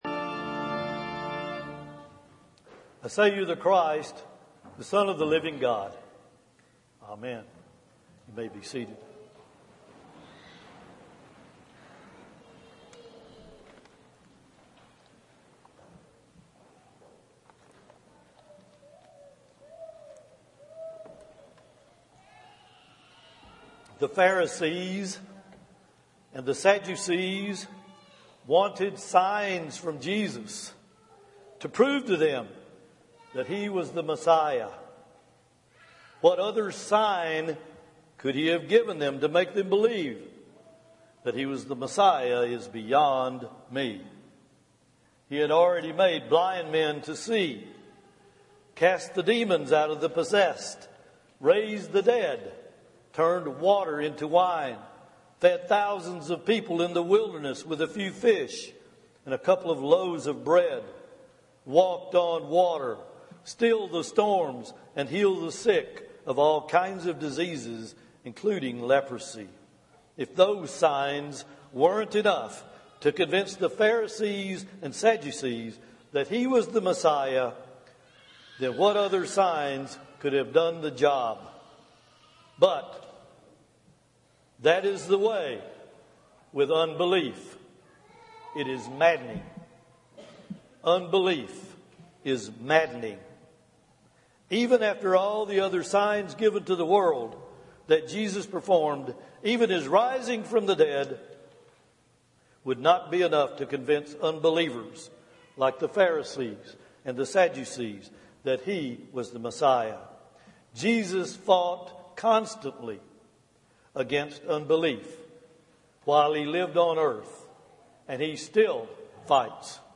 Matthew 16:13-20 Audio Sermon